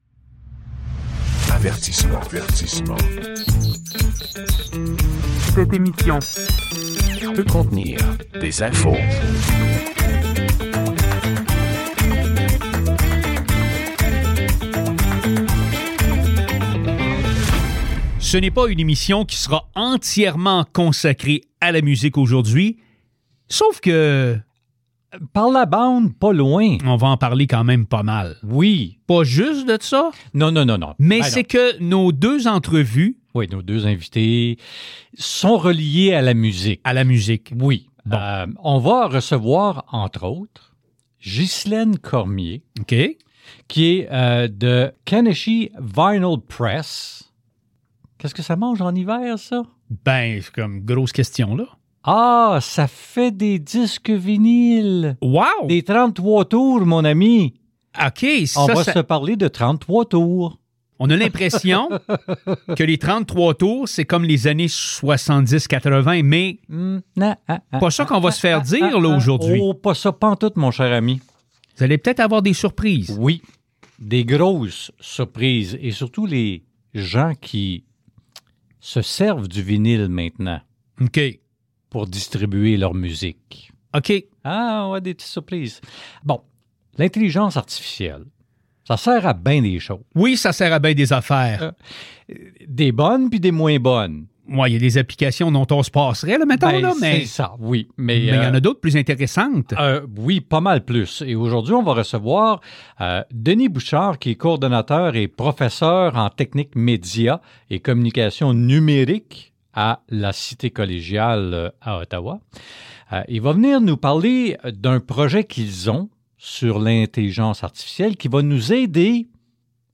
Le milliardaire Elon Musk contraindra les nouveaux usagers de son réseau social X à déverser un frais minime pendant 3 mois afin d'avoir le privilège de publier sur la plateforme. On vous apprend aussi que l'entreprise alimentaire Heinz et Mattel se sont associés pour lancer un produit pour le moins surprenant. En entrevue